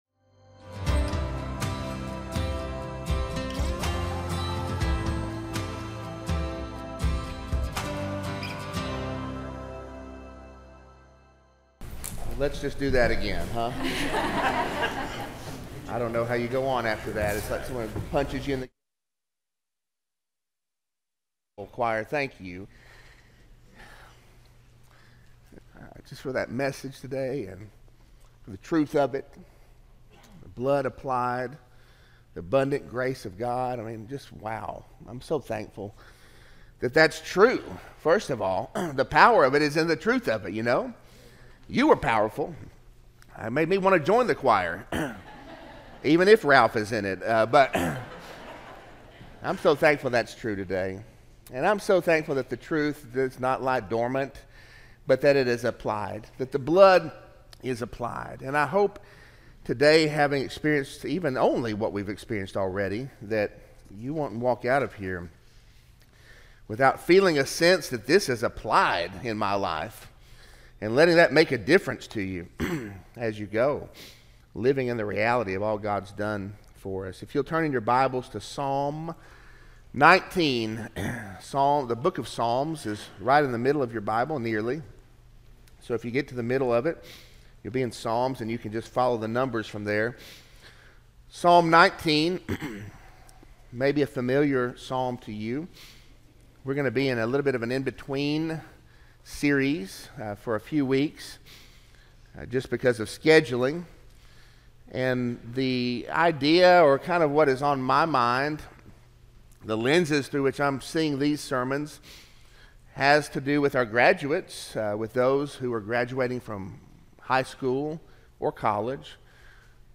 Sermon-4-28-24-audio-from-video.mp3